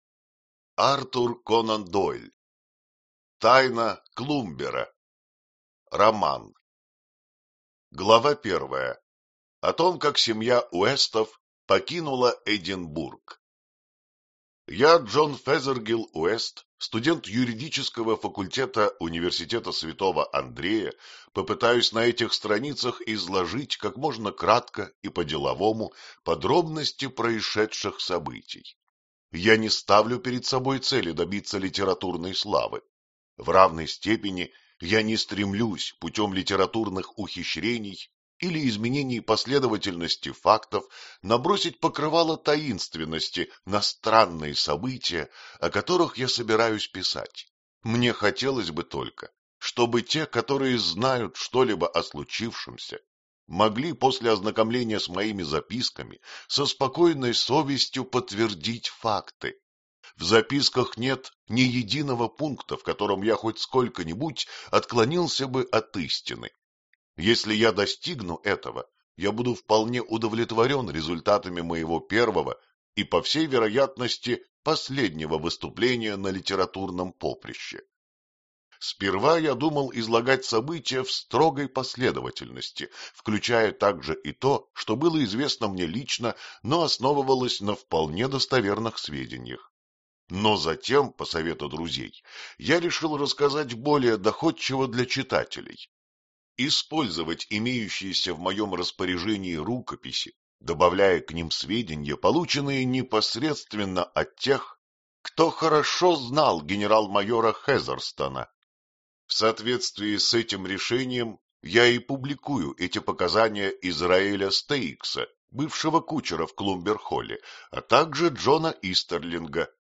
Аудиокнига Тайна Клумбера | Библиотека аудиокниг